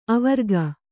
♪ avarga